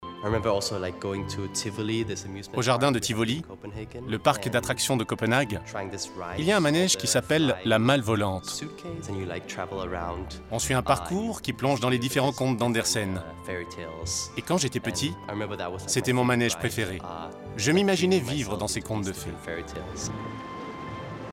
Voice over- Arte